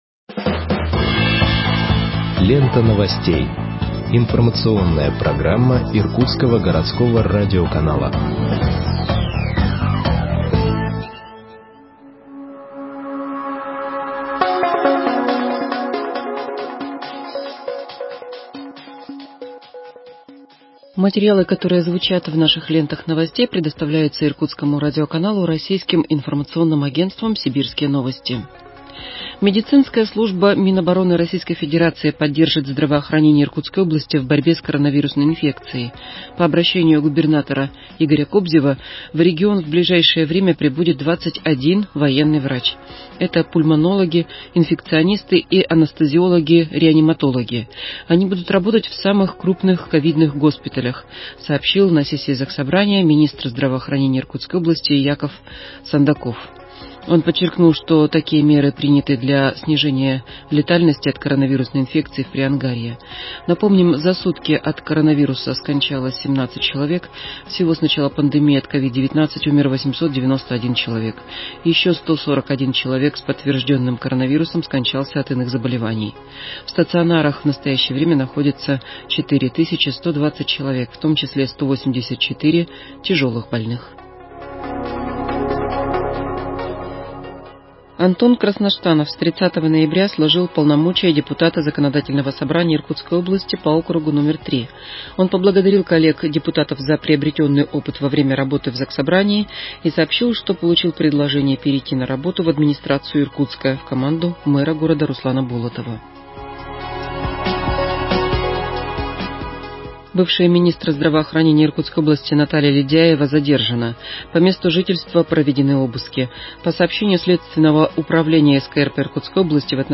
Выпуск новостей в подкастах газеты Иркутск от 01.12.2020